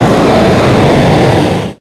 Audio / SE / Cries / GROUDON.ogg